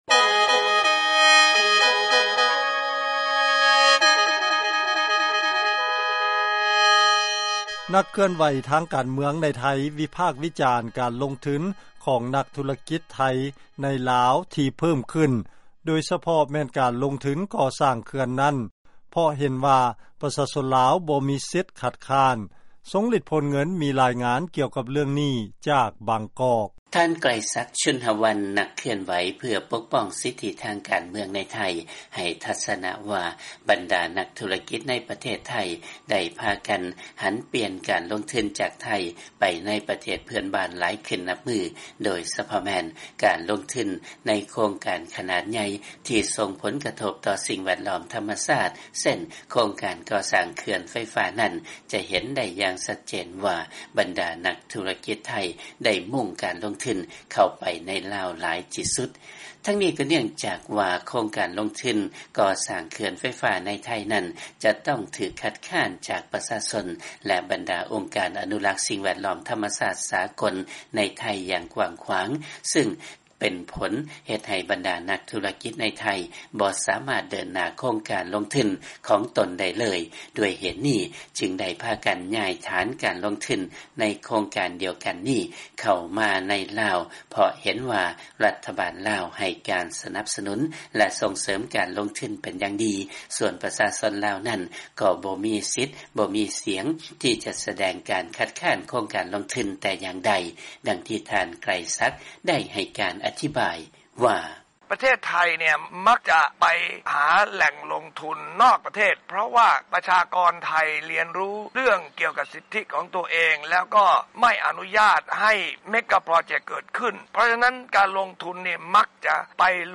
ເຊີນຟັງລາຍງານ ນັກເຄື່ອນໄຫວ ວິຈານການລົງທຶນ ໃນເຂື່ອນ ໄຟຟ້າຢູ່ລາວ ຂອງນັກທຸລະກິດໄທ